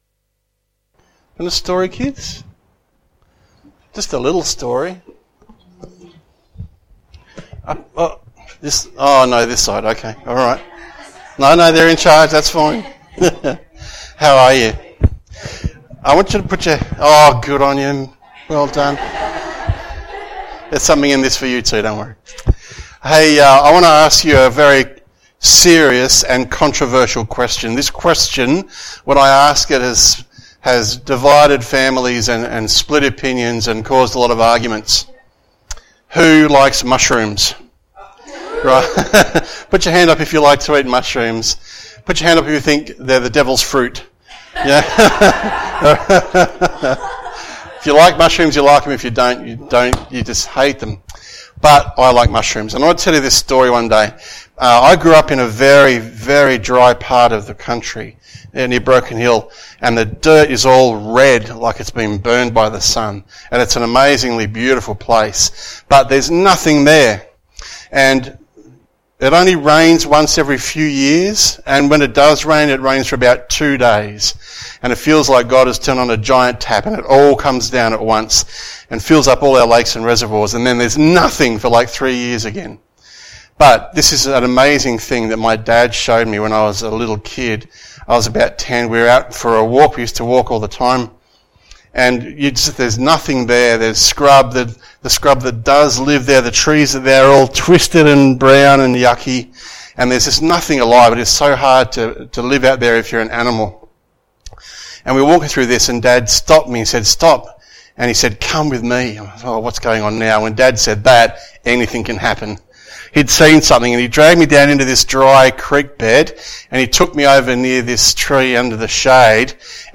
Children's Stories
SABBATH SERMONS